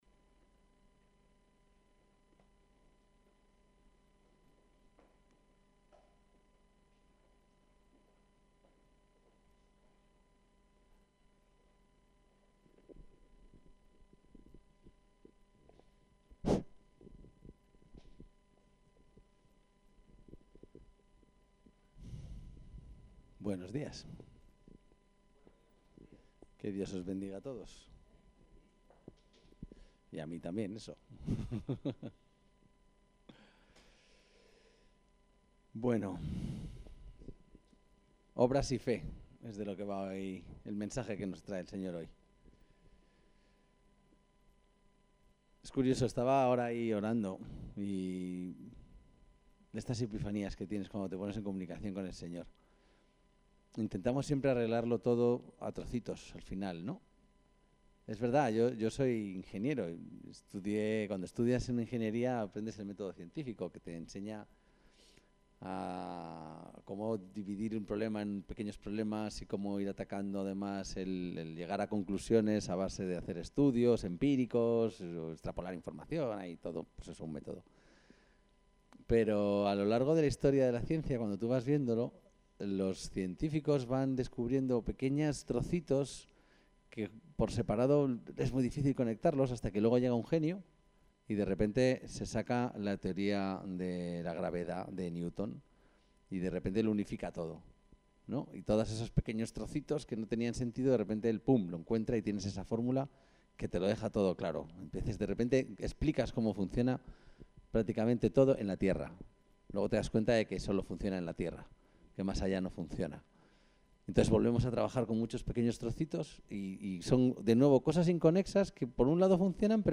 El texto de la predicación se puede descargar aquí Fe y Obras